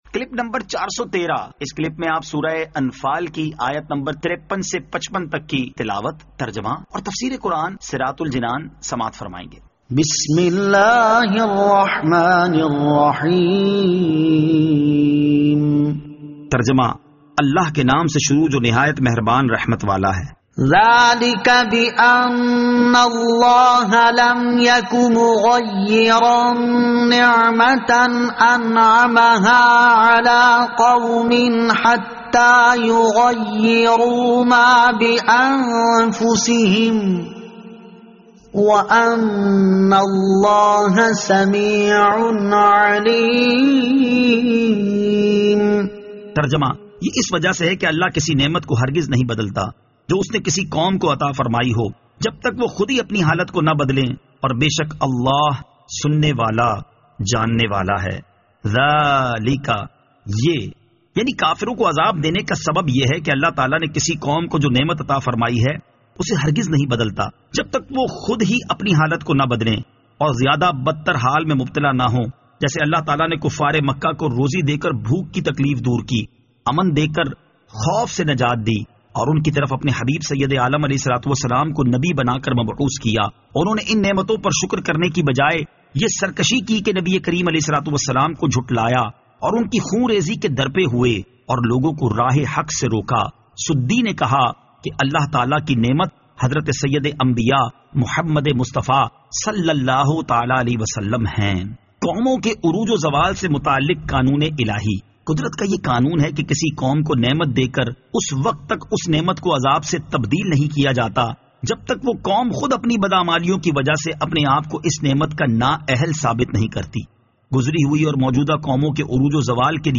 Surah Al-Anfal Ayat 53 To 55 Tilawat , Tarjama , Tafseer